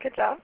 Seven prosodic variants of good job (au files):
upturn
upturn.au